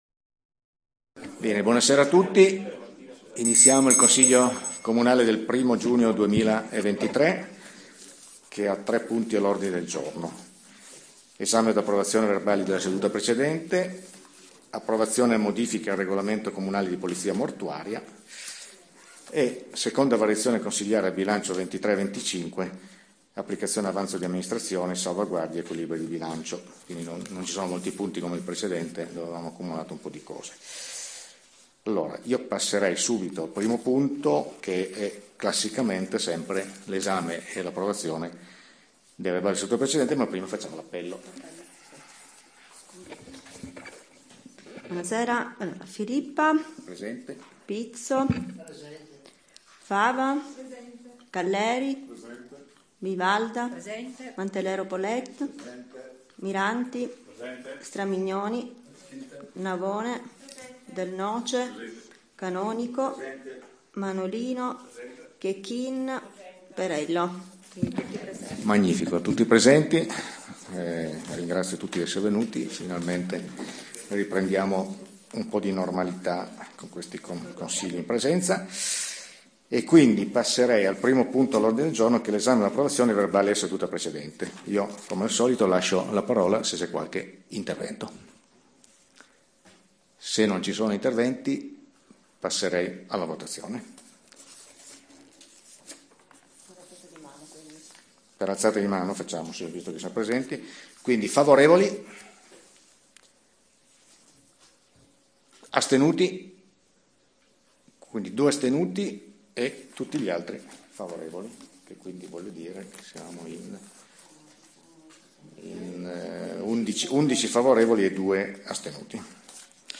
Registrazione Consiglio comunale Comune di Pecetto Torinese